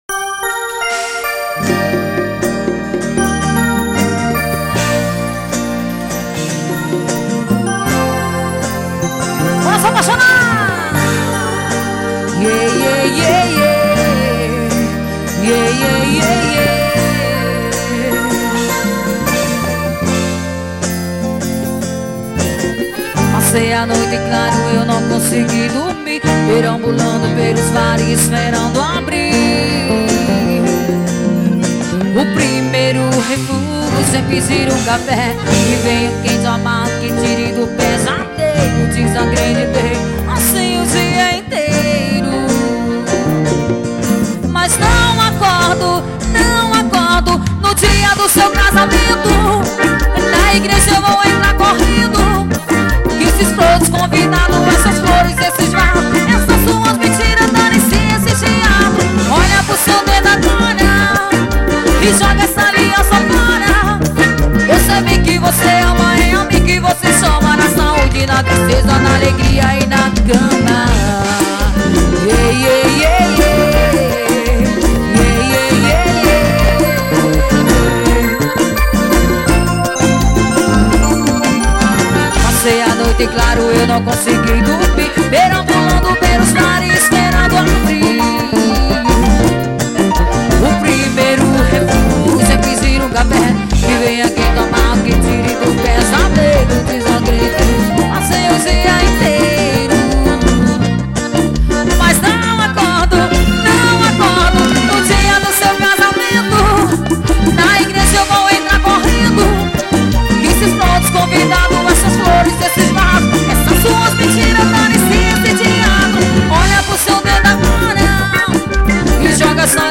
forro pegado.